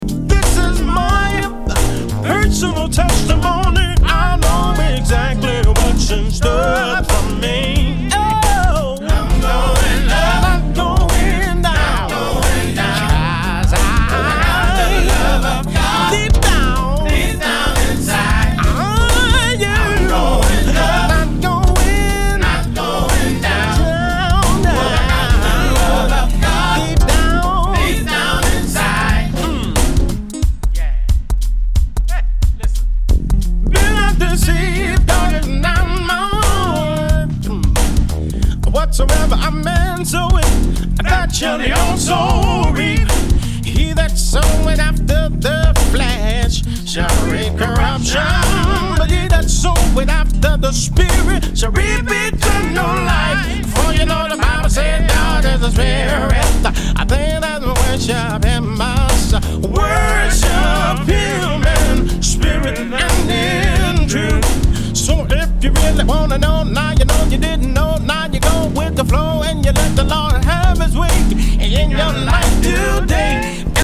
R&B GOSPEL